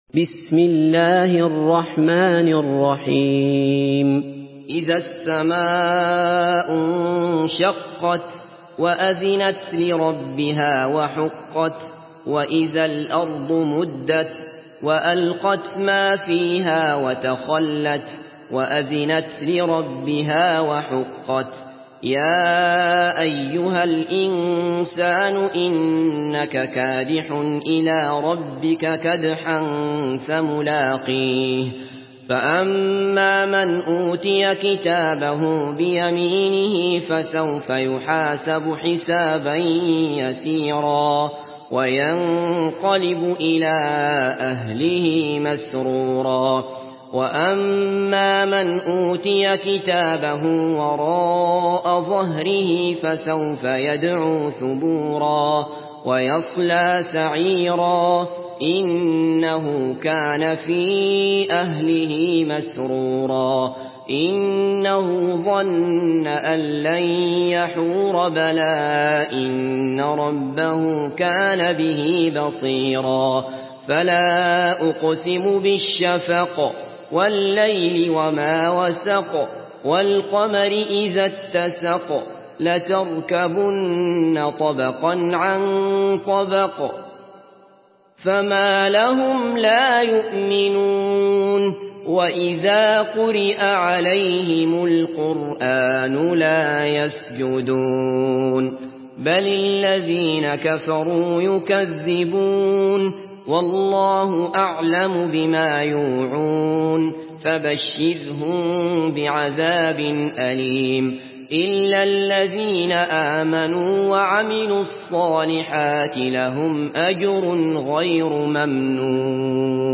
سورة الانشقاق MP3 بصوت عبد الله بصفر برواية حفص عن عاصم، استمع وحمّل التلاوة كاملة بصيغة MP3 عبر روابط مباشرة وسريعة على الجوال، مع إمكانية التحميل بجودات متعددة.
مرتل